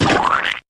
lancersplat.wav